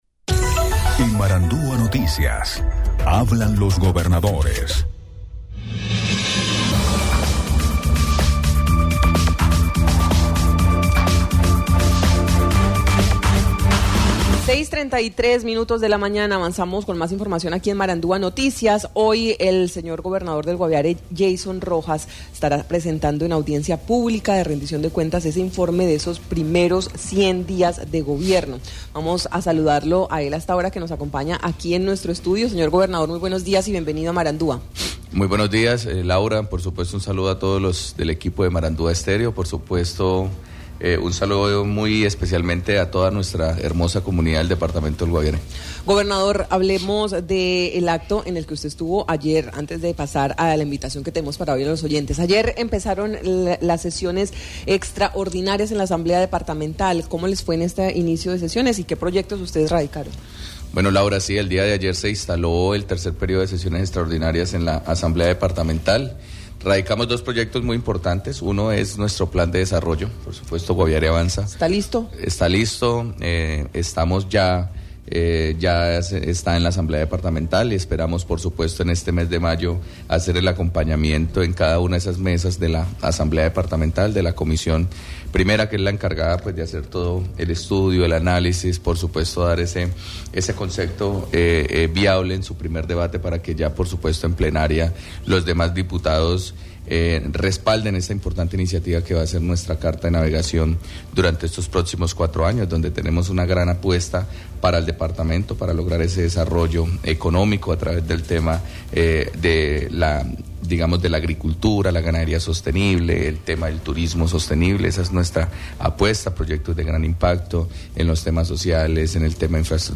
Yeison Rojas, gobernador del Guaviare, en entrevista con Marandua Noticias se refirió inicialmente al acto de instalación del tercer periodo de sesiones extraordinarias